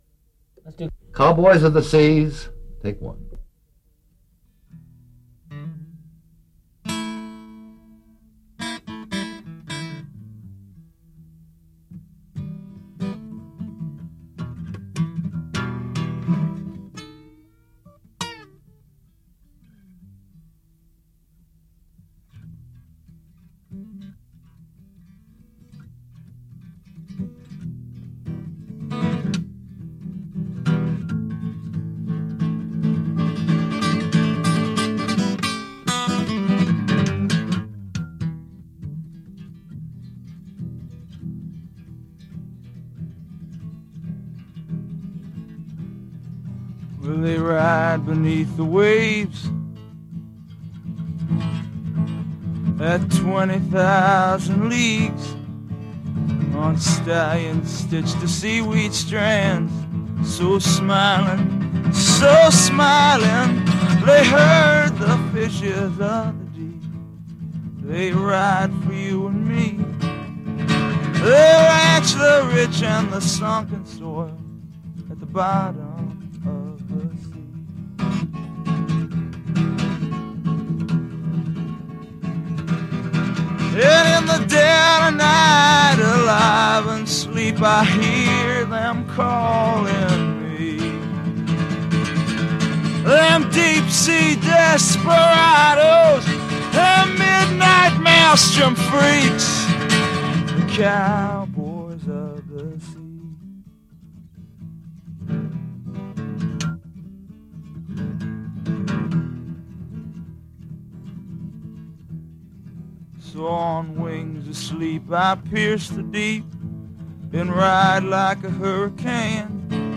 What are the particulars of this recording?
solo acoustic version